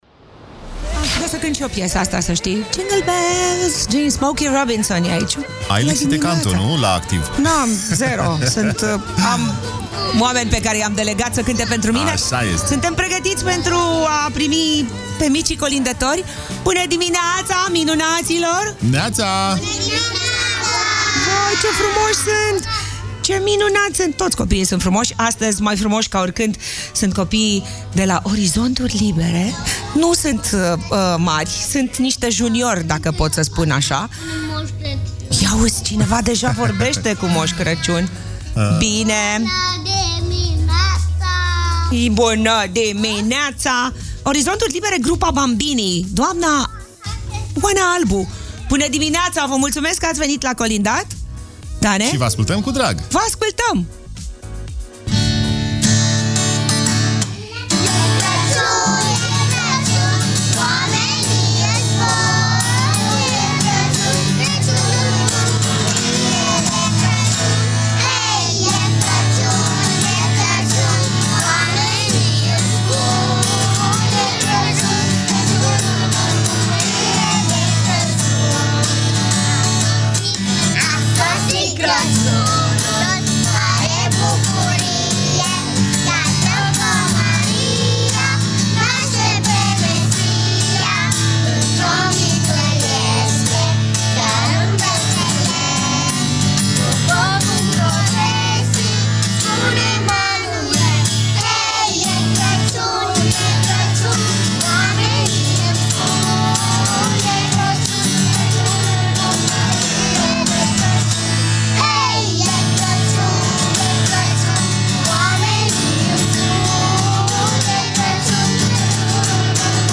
Magic Morning - 12 Decembrie - Colindători, Copiii din Grupa Bambini de la Montessori Orizonturi Libere